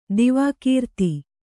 ♪ divākīrti